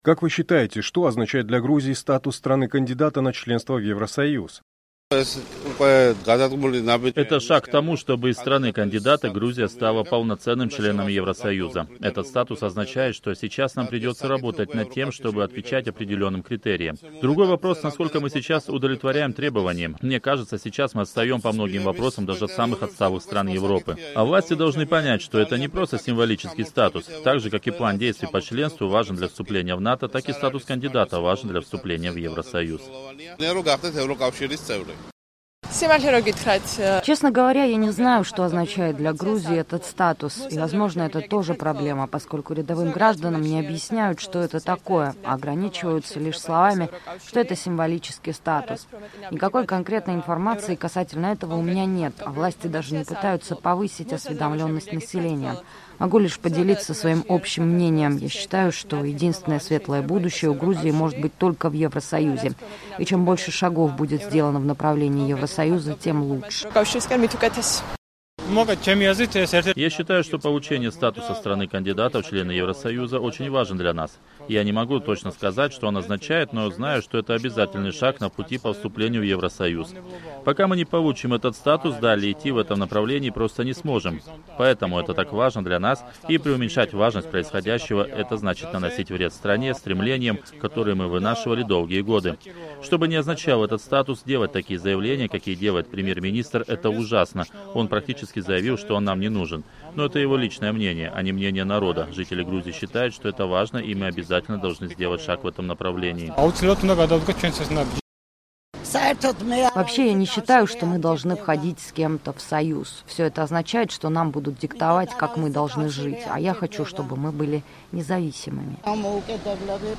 Грузия в ожидании решения Еврокомиссии о статусе страны-кандидата в ЕС. «Эхо Кавказа» выясняло, что знают жители Тбилиси о содержании этого статуса.